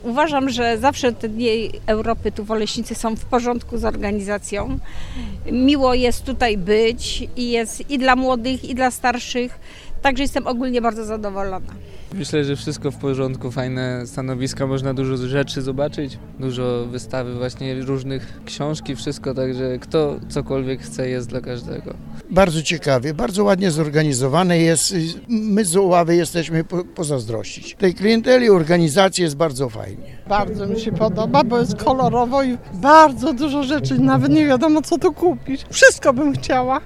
O zdanie nt. odbywających się wydarzeń zapytaliśmy też samych odwiedzających.